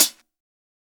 Cardiak Hat LD.wav